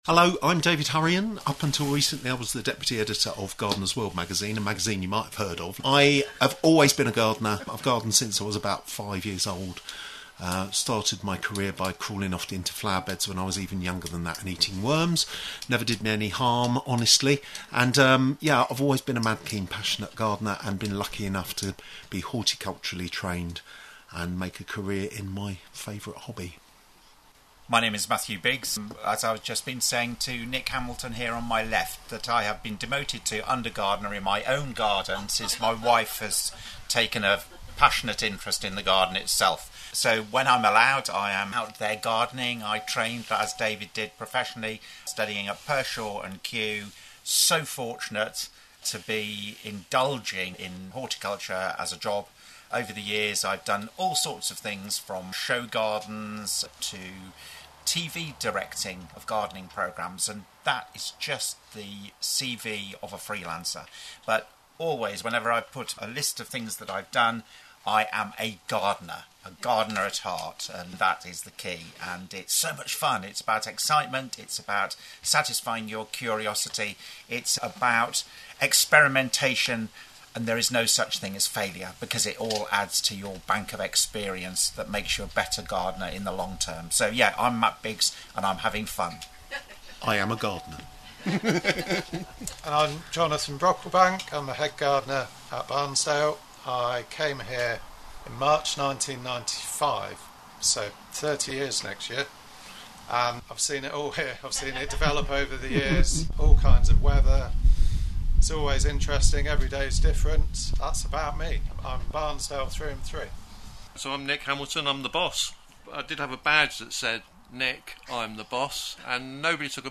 Recorded on 4th August 2024 at Barnsdale Gardens
Featuring questions from the floor, with expert gardening advice.